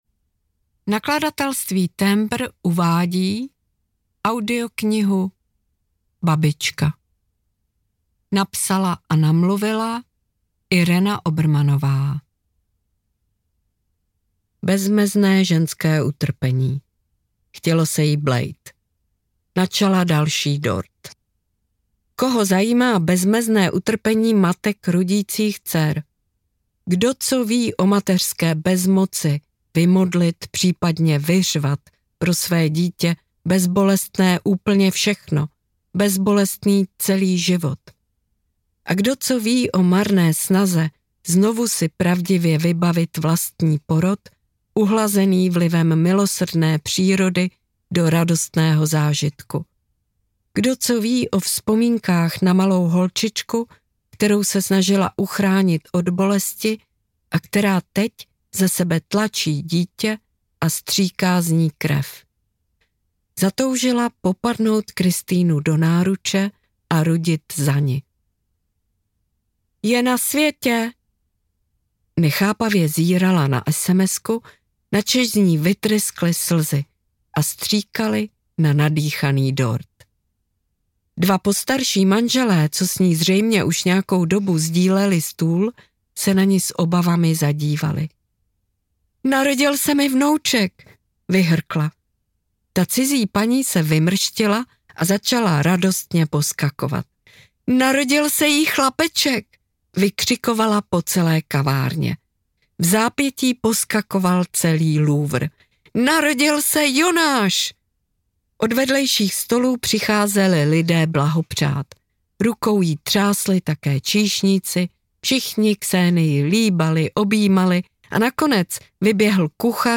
Babička audiokniha
Audiokniha Babička, kterou napsala a namluvila Irena Obermannová. Knížka o stárnutí, mládnutí a sexu.
Ukázka z knihy
• InterpretIrena Obermannová